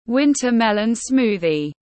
Sinh tố bí đao tiếng anh gọi là winter melon smoothie, phiên âm tiếng anh đọc là /’wintə ‘melən ˈsmuː.ði/